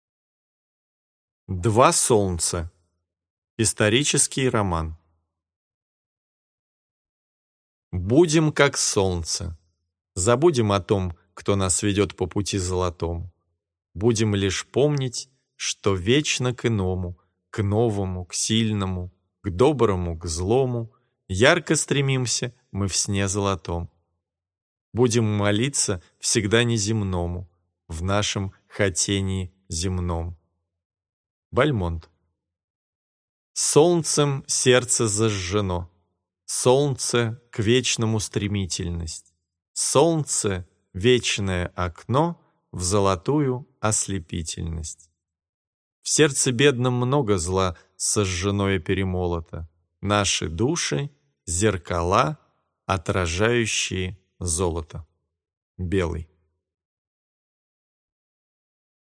Аудиокнига Два солнца | Библиотека аудиокниг